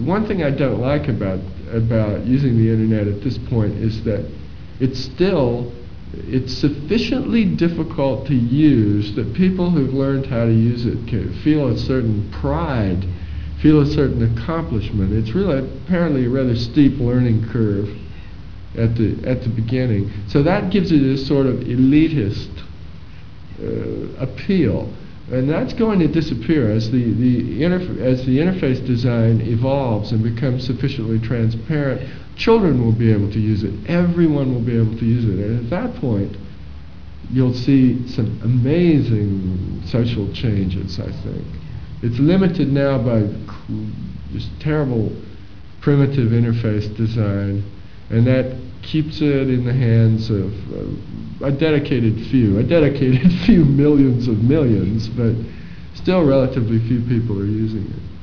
Listen to William Gibson talk about the elitist appeal of the Internet